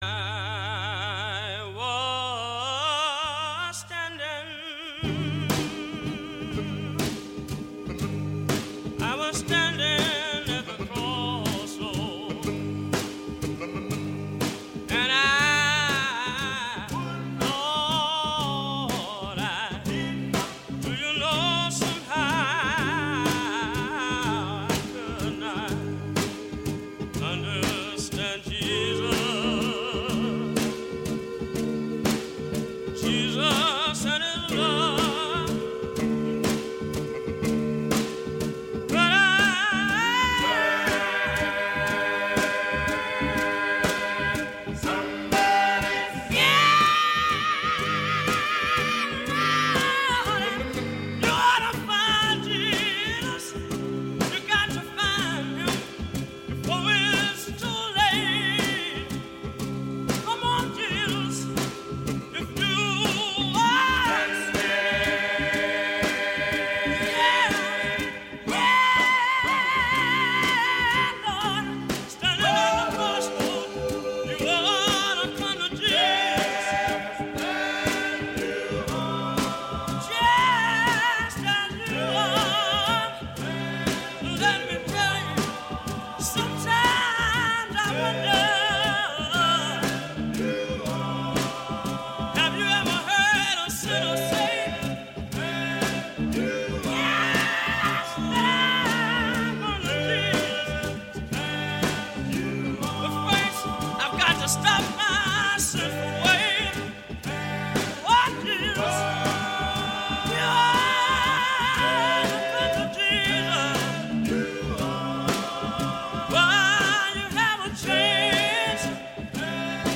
Live in the studios of WBIL-AM Tuskegee, 2003.